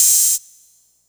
TM-88 Hats [Open Hat 3].wav